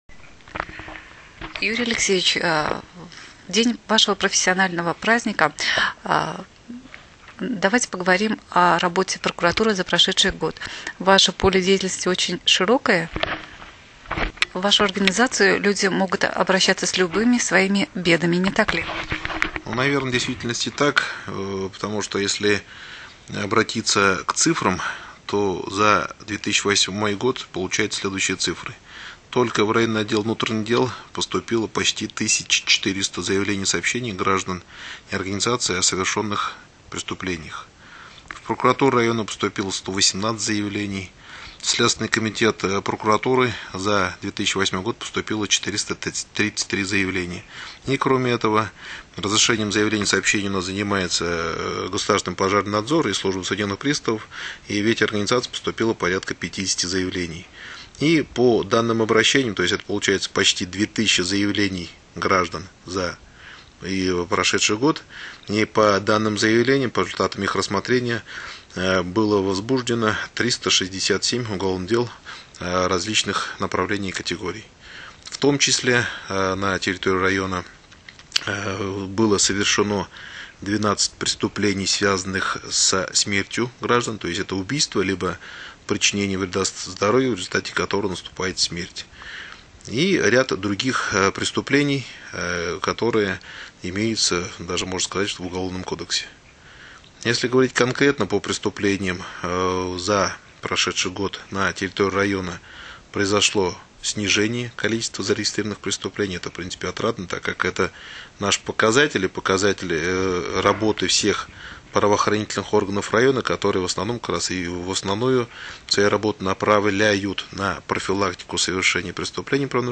Аудиозапись интервью